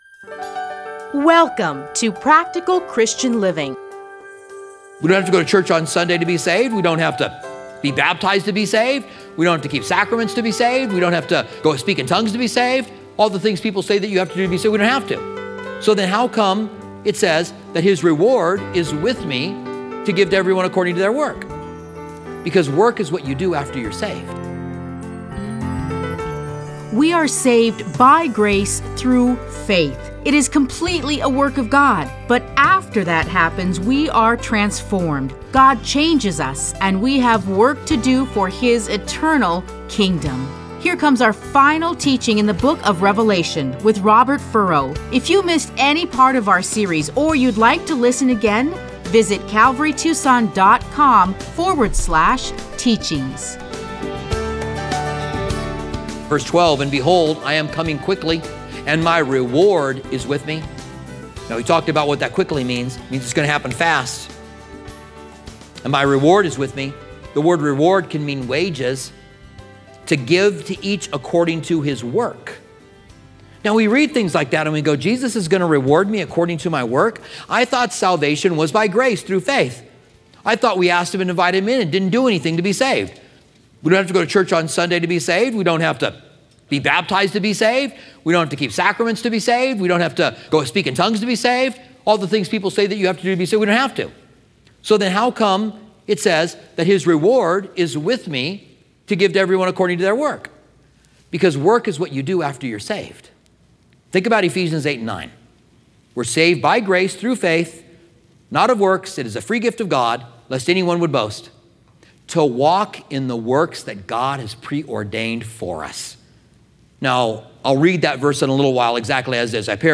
Listen to a teaching from Revelation 22:6-21.